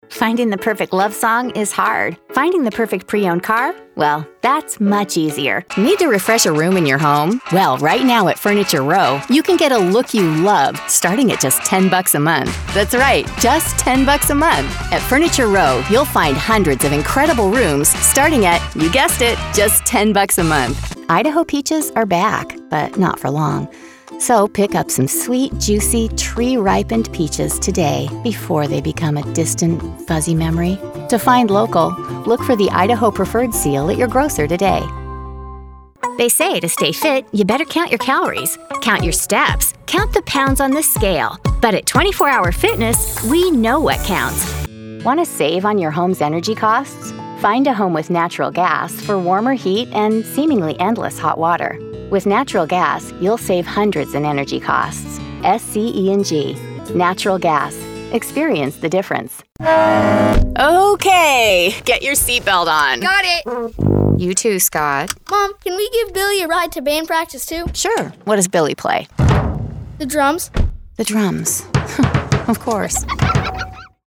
Female
Confident, Cool, Engaging, Friendly, Natural, Warm, Versatile
US General, US West Coast, Canadian West Coast
ASTRAY PODCAST 15 - PROMO.mp3
Microphone: Neumann TLM 103 & Sennheiser 416